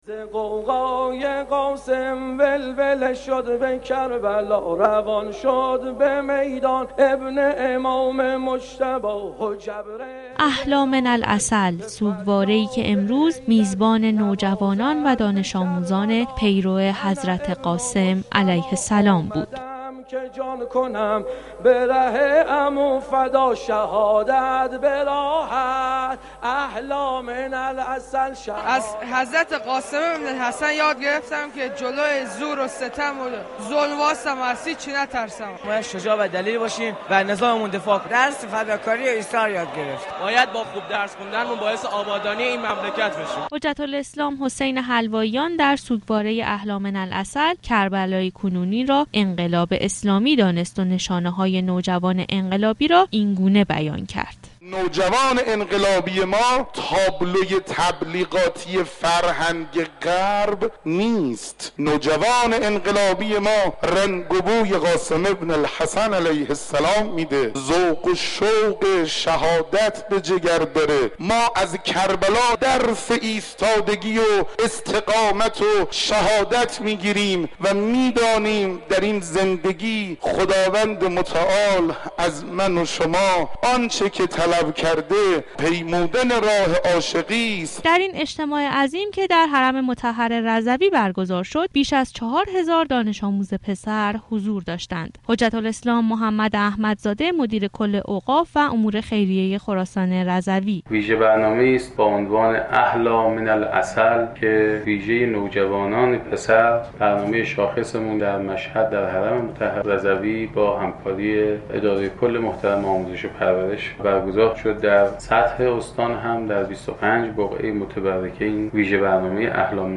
در حرم منور امام رضا(ع) دانش آموزان عاشورایی آمده بودند تا به یاد حضرت قاسم ابن الحسن(ع)یك صدا فریاد لبیك یا حسین (ع) سر دهند.